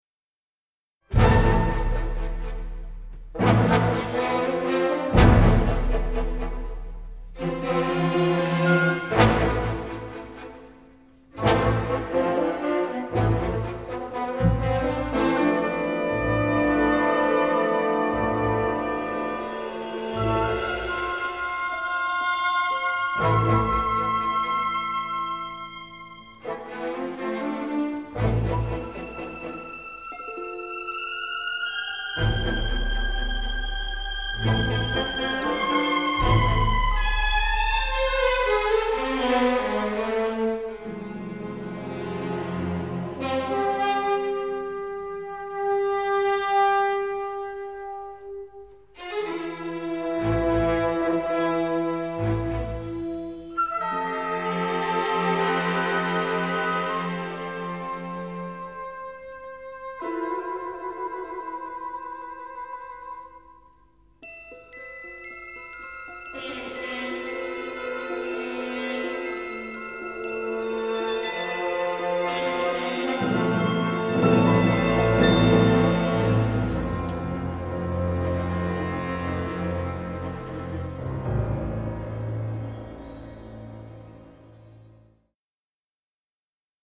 Trumpets, French horns keep everything at peak excitement.
Recorded in Germany.